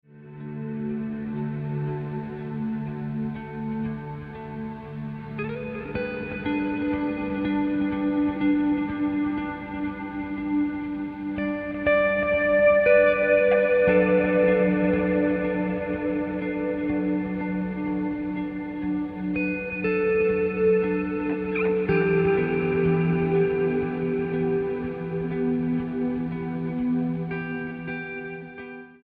STYLE: Rock
full of swirling reverb